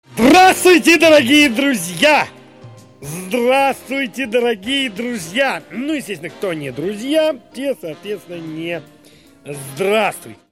• Качество: 192, Stereo
мужской голос